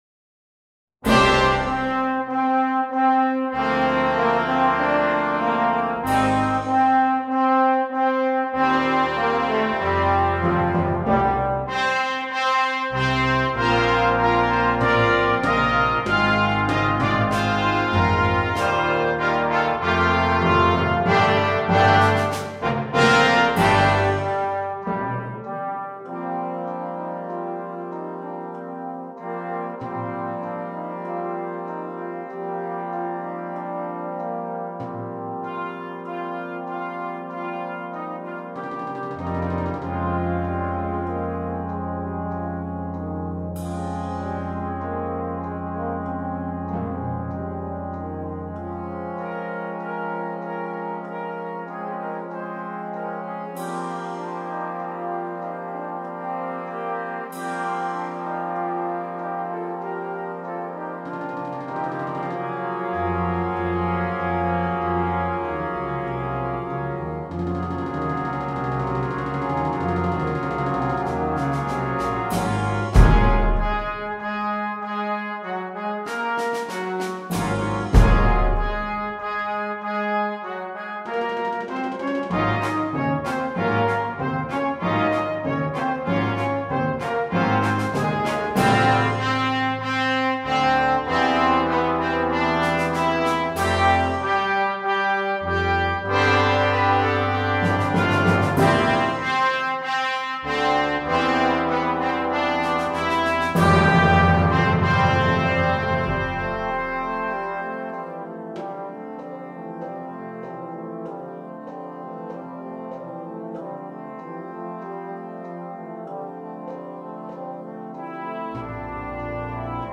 2. Orquesta de viento juvenil (flex)
8 Partes y Percusión
sin un solo instrumento
Particella 1 en do (8va): Flauta
Particella 1 en sib: 1er Clarinete, 1er Trompeta / Corneta
Percusión